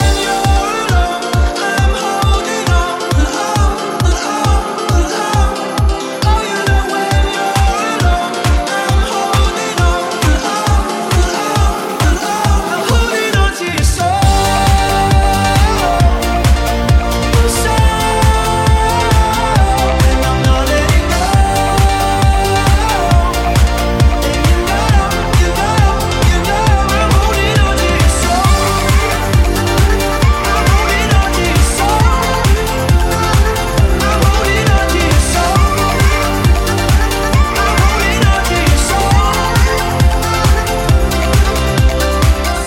Genere: pop, club, deep, remix